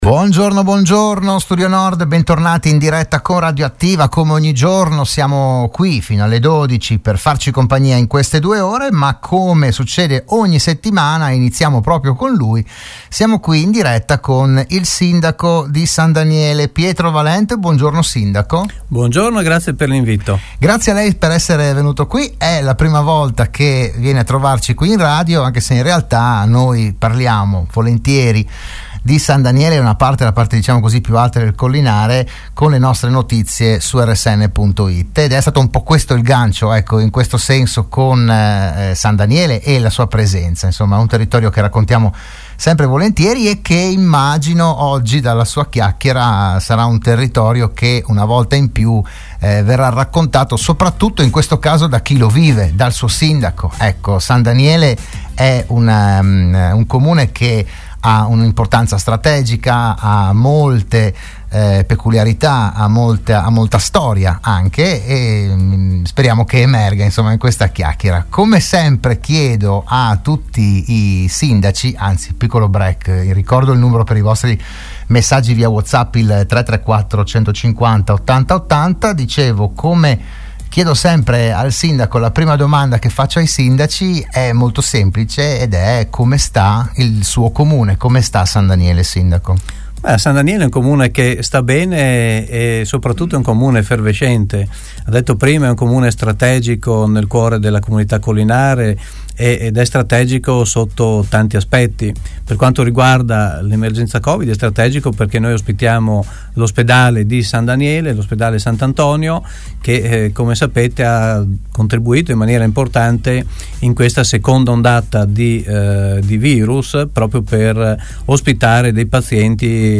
Ogni settimana il primo cittadino di un comune dell’Alto Friuli è ospite in studio, in diretta (anche video sulla pagina Facebook di RSN), per parlare del suo territorio, delle problematiche, delle iniziative, delle idee, eccetera.
All’ottavo appuntamento del 2021 ha partecipato il sindaco di San Daniele del Friuli Pietro Valent.